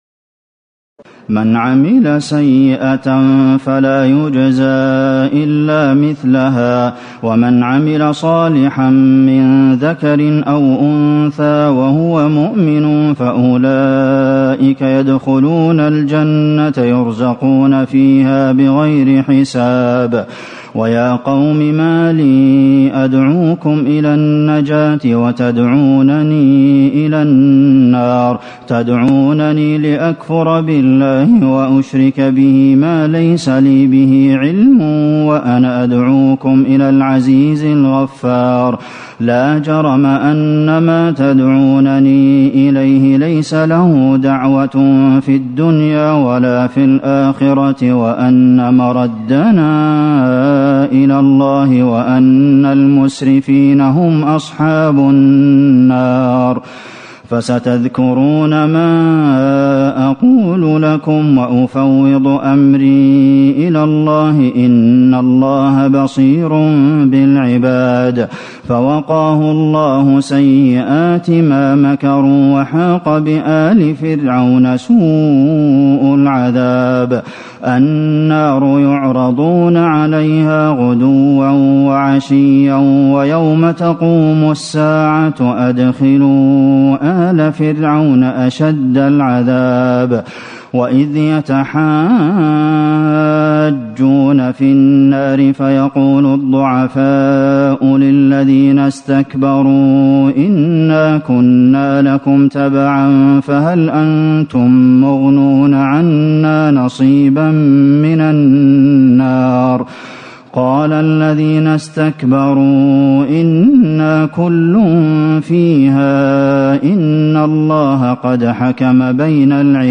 تراويح ليلة 23 رمضان 1437هـ من سور غافر (40-85) وفصلت (1-46) Taraweeh 23 st night Ramadan 1437H from Surah Ghaafir and Fussilat > تراويح الحرم النبوي عام 1437 🕌 > التراويح - تلاوات الحرمين